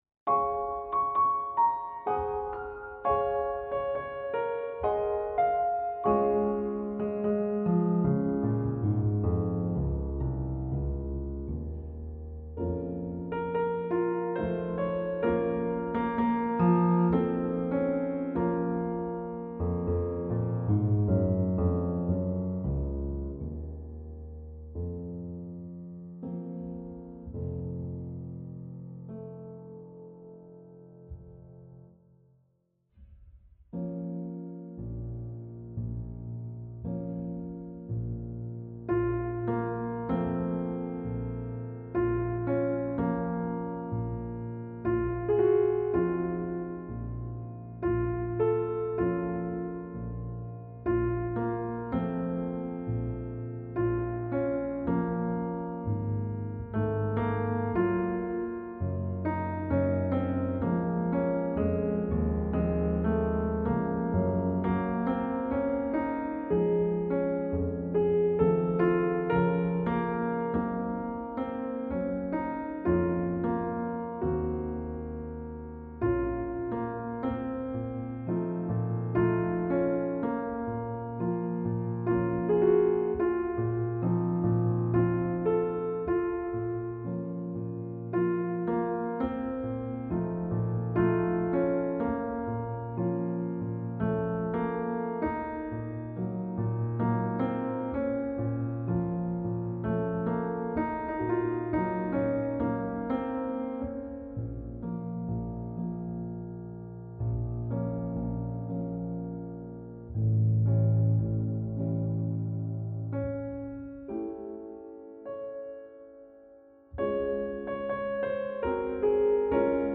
Style: Classical
piano